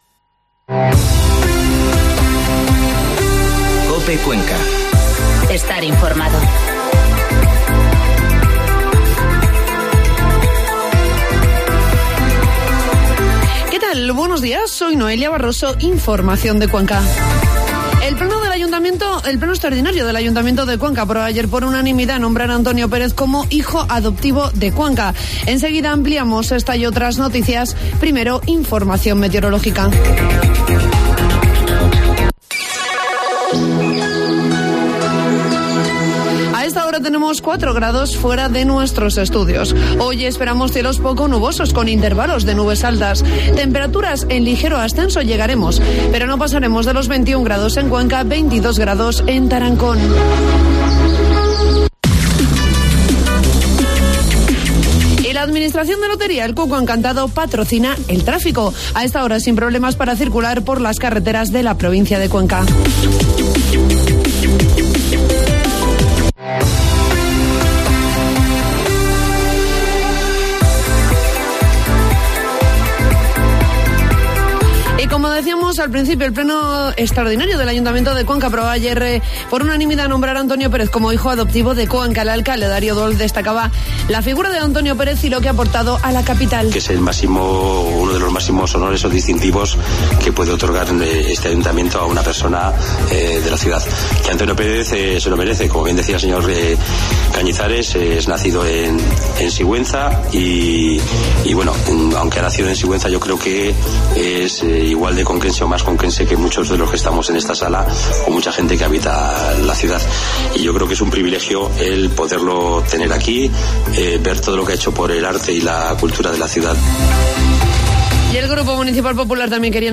Informativo matinal COPE Cuenca 17 de octubre